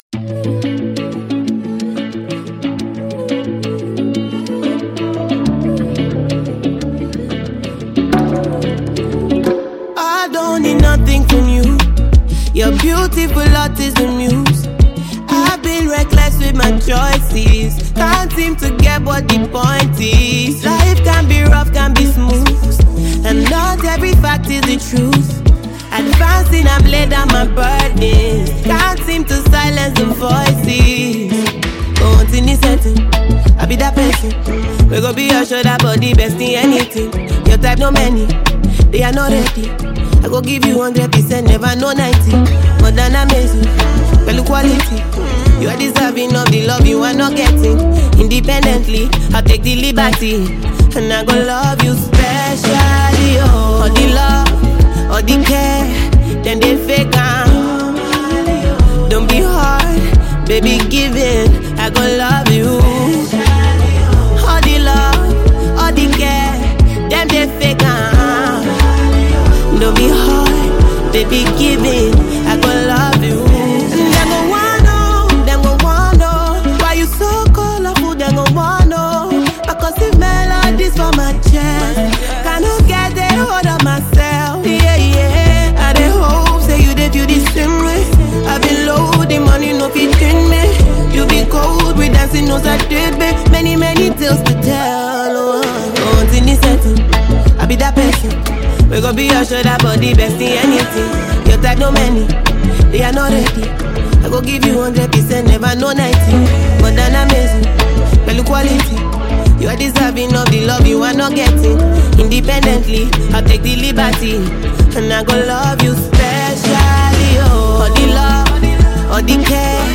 Renowned Nigerian Afrobeats talent and performer
inspiring vibe
The music scene is excited to embrace this energetic release